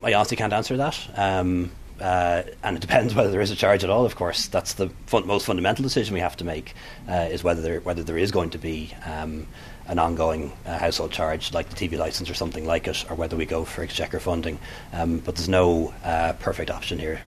The Taoiseach was asked if a new charge would end up being higher than the existing one: